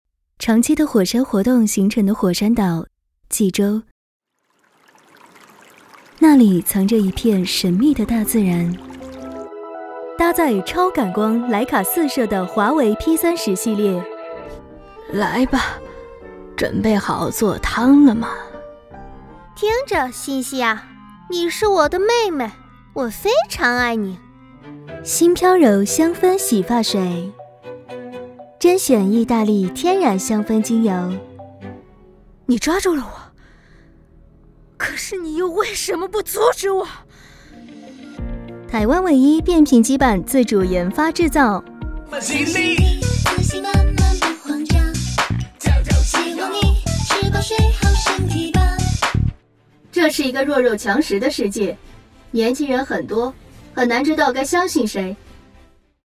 Male 20s , 30s , 40s German (Native) , American English , British English Approachable , Assured , Authoritative , Bright , Bubbly , Character , Confident , Conversational , Cool , Corporate , Energetic , Engaging , Friendly , Funny , Gravitas , Natural , Posh , Reassuring , Sarcastic , Smooth , Soft , Streetwise , Upbeat , Versatile , Wacky , Warm , Witty , Young